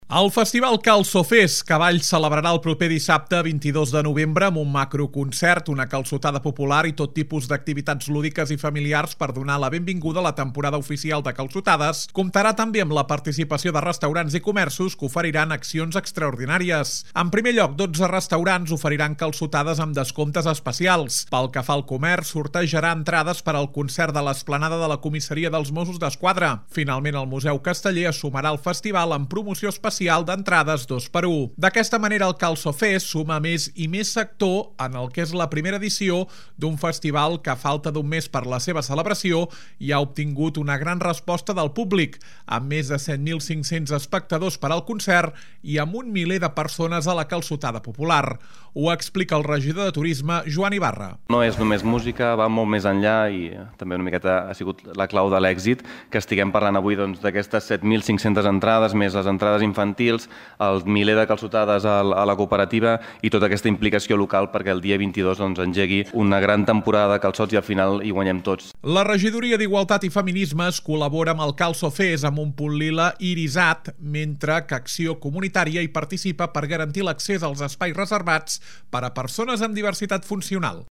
Ho explica el regidor de Turisme, Joan Ibarra.